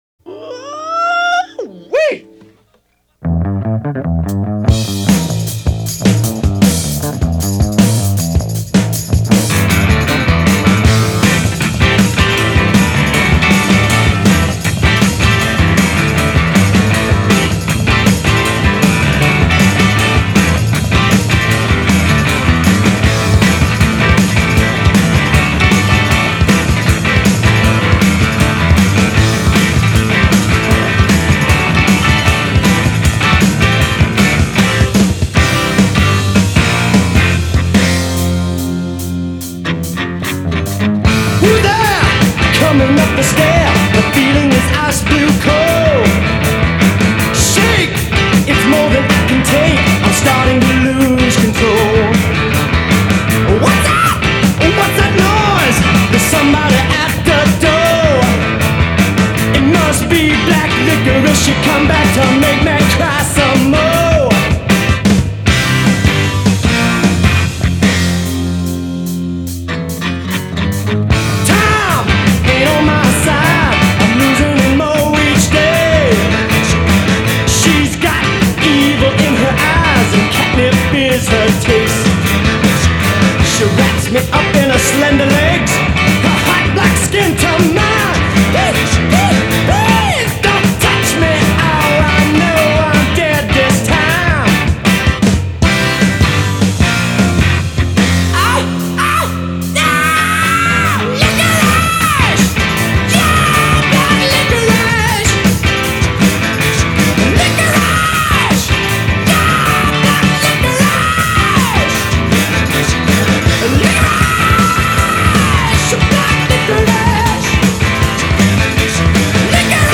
Der dumpf hämmernde Bass
Und die perfekten Schlagzeugwirbel